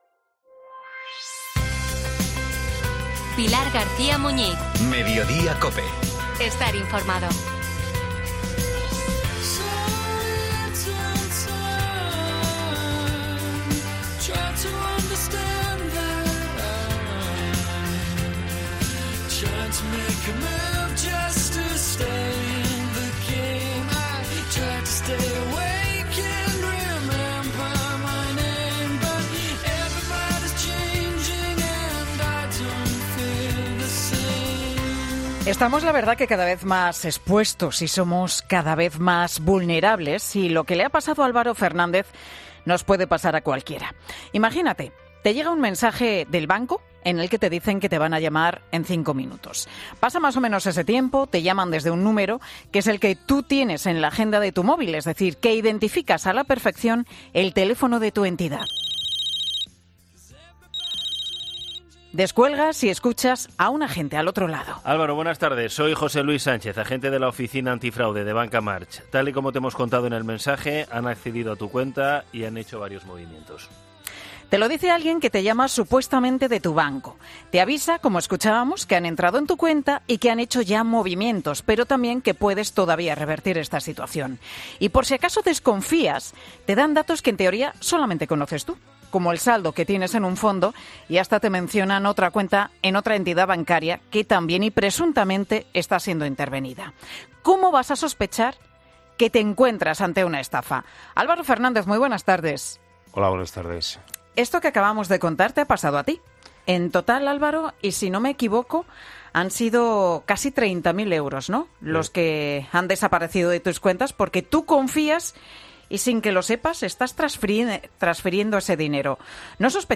en el estudio de Mediodía COPE